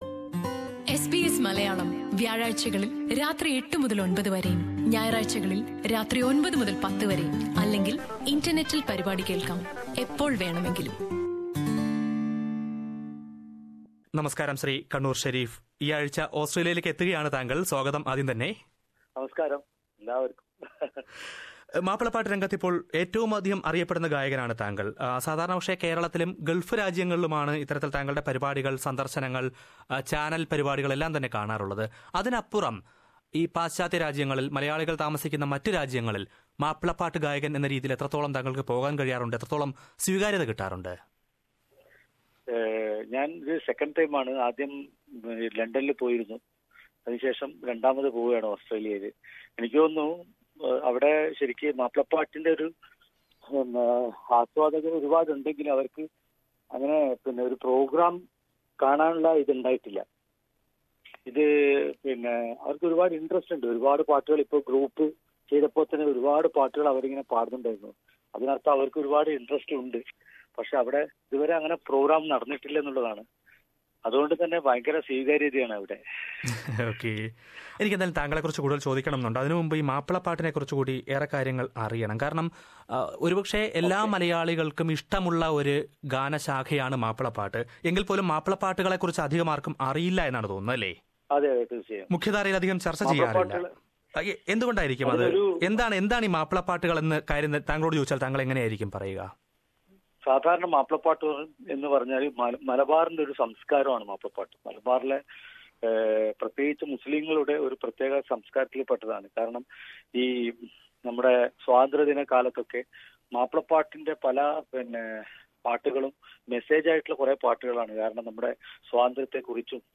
മാപ്പിളപ്പാട്ട് രംഗത്ത് ഇപ്പോഴത്തെ ഏറ്റവും പ്രശസ്തമായ ശബ്ദമാണ് കണ്ണൂർ ഷെരീഫ്. സിഡ്നിയിലും ബ്രിസ്ബൈനിലും ഈശൽരാവൊരുക്കാനായി അദ്ദേഹം ഓസ്ട്രേലിയയിലേക്ക് എത്തുന്നുണ്ട്. മാപ്പിളപ്പാട്ടുകളുടെ സംസ്കാരത്തെക്കുറിച്ചും പ്രത്യേകതകളെക്കുറിച്ചും കണ്ണൂർ ഷെരീഫ് എസ് ബി എസ് മലയാളവുമായി സംസാരിക്കുന്നത് കേൾക്കാം...